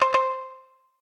shamisen_cc.ogg